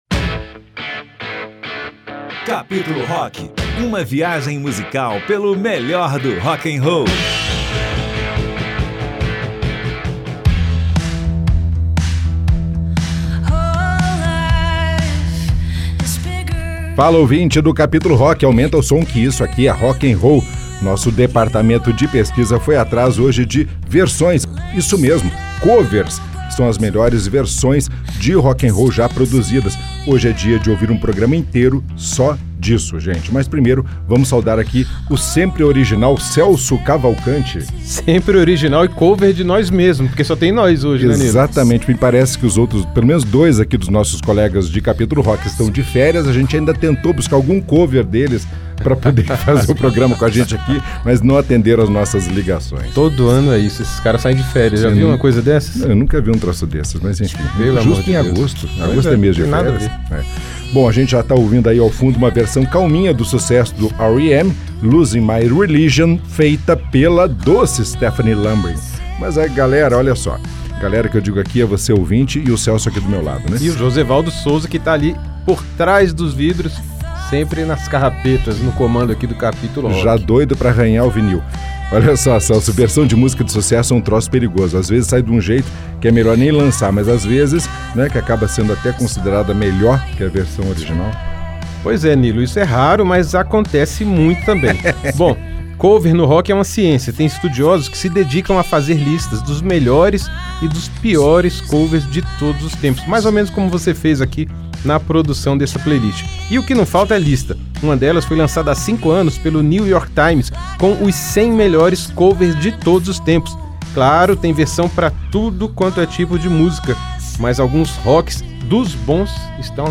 Tem músicas que nem rock eram, mas ficaram bonitas com a roupagem mais pesada. Outras, ficaram mais leves.